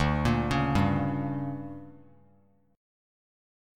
DM7sus4#5 chord